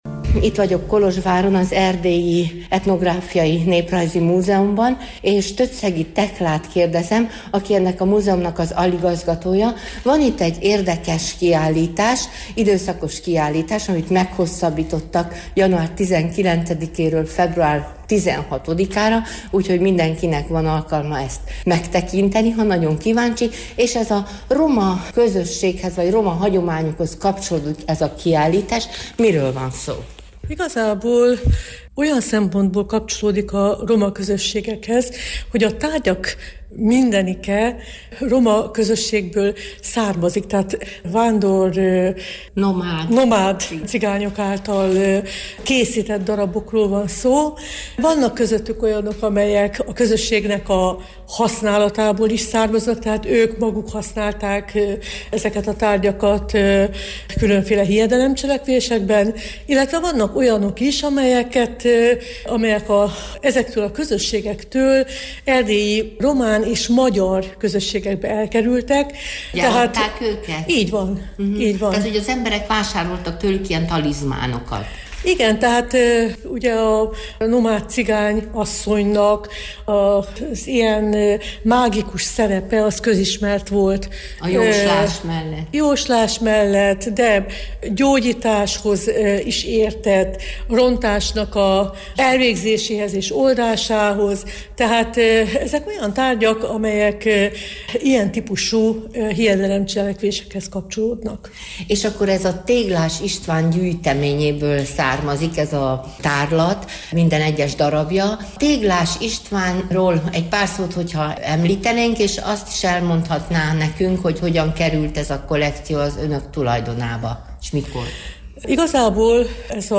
Az interjú során megtudhatjuk, hogyan került gyűjteménye a múzeum tulajdonába, és néhány igazán egyedi darabról, kiállítási tárgyról is elbeszélgetünk, mit jelképezett, mire volt jó, milyen hiedelemhez kapcsolódik.